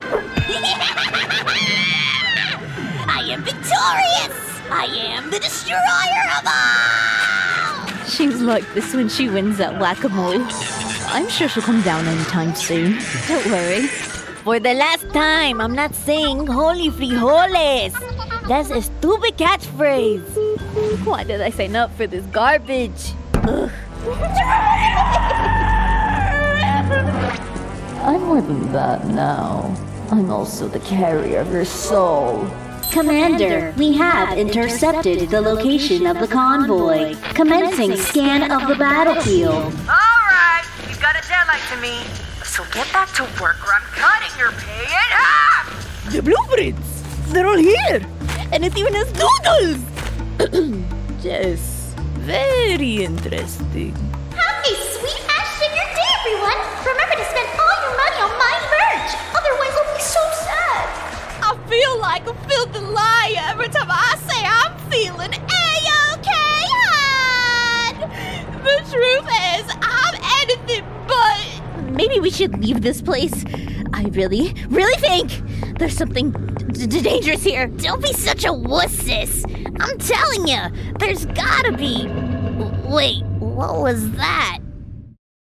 Child, Teenager, Young Adult, Adult, Mature Adult
latin american Accents: british rp | character mexican | character mexican | natural southern us | character spanish latin american | natural spanish latin american | natural Voice Filters: VOICEOVER GENRE ANIMATION